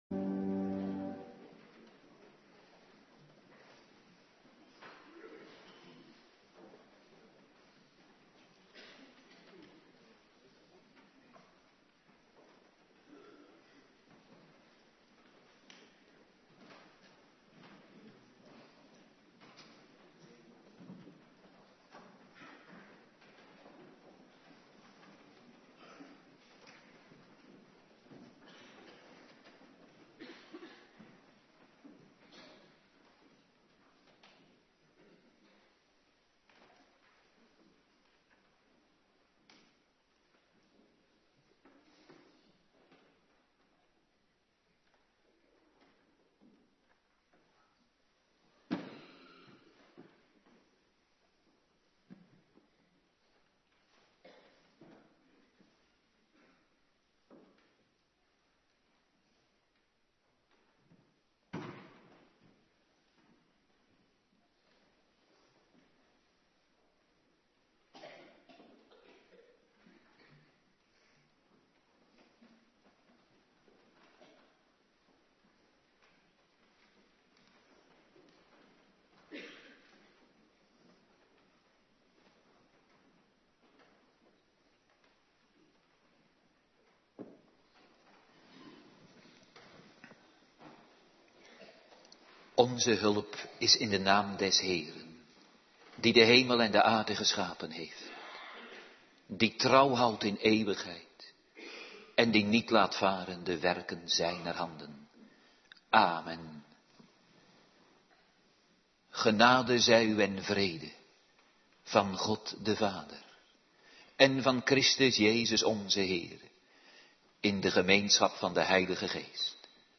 Morgendienst
09:30 t/m 11:00 Locatie: Hervormde Gemeente Waarder Agenda: Kerkdiensten Terugluisteren Markus 9:14-29